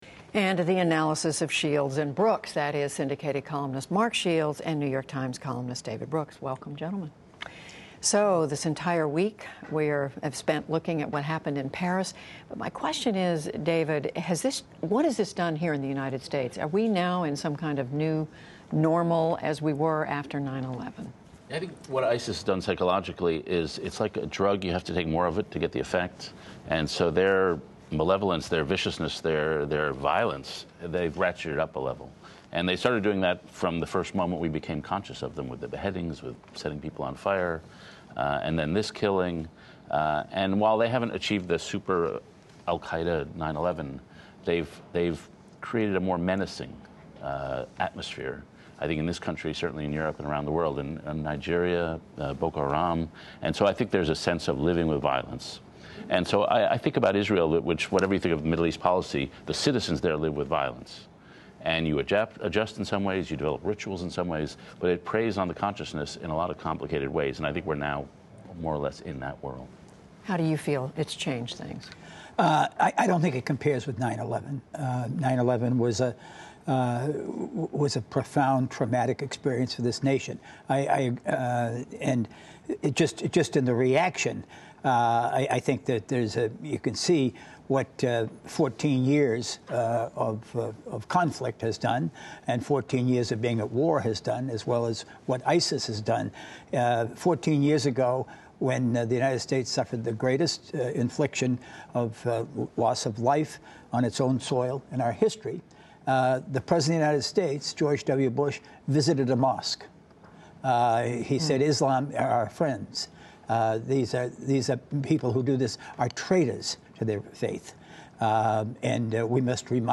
Syndicated columnist Mark Shields and New York Times columnist David Brooks join Judy Woodruff to discuss the week’s news, including the political and psychological aftermath of the Paris attacks, 2016 candidates speak out on the refugee crisis and fighting the Islamic State, plus reassessing President Obama’s strategy in Syria.